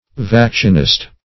Vaccinist \Vac"ci*nist\, n.